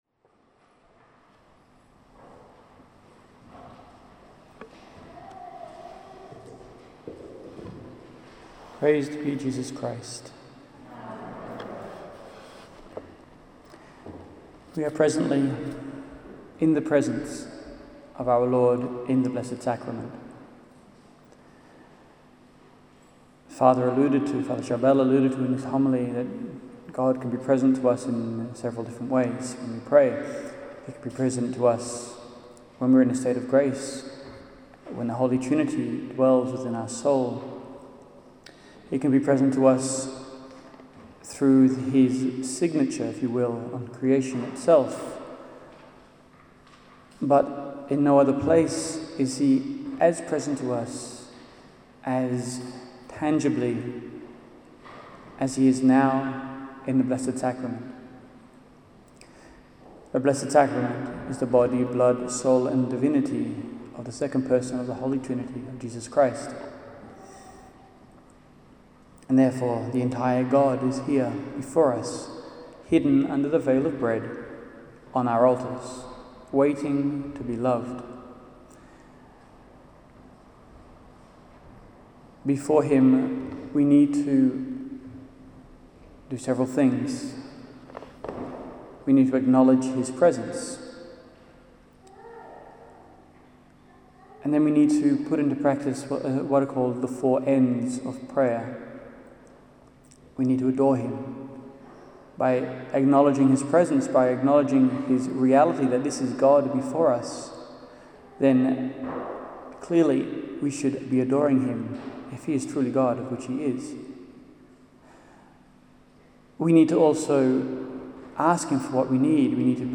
speaks on the Blessed Sacrament during the "Day With Mary" held at St. Mary Parish in Leederville, Western Australia